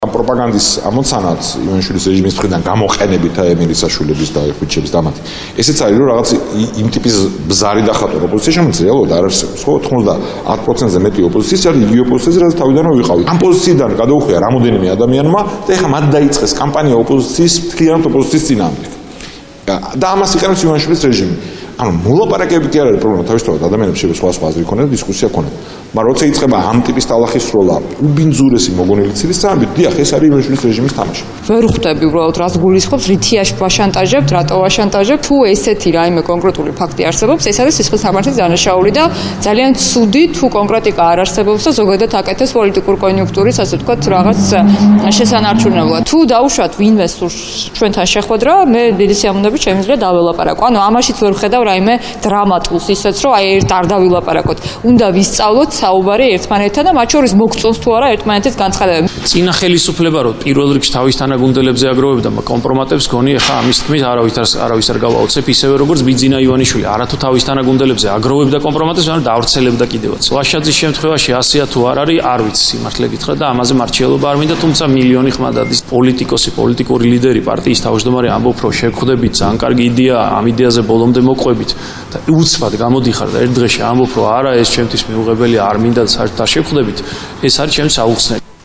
მოისმინეთ გიგა ბოკერიას,ხატია დეკანოიძის და ალეკო ელისაშვილის კომენტარები